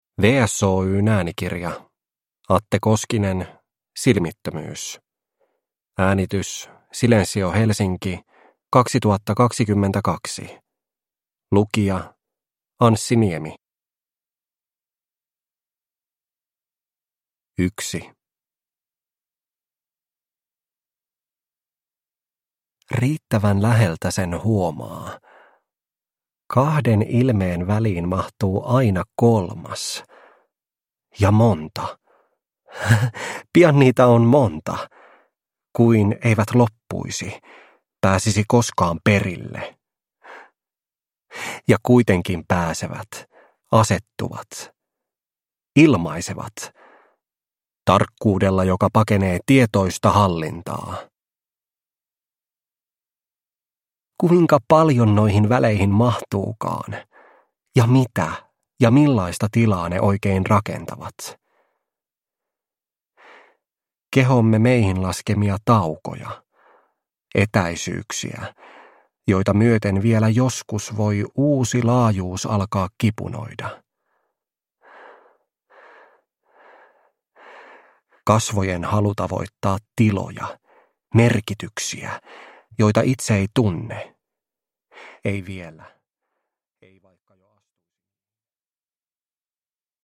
Silmittömyys – Ljudbok – Laddas ner